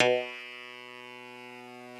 genesis_bass_035.wav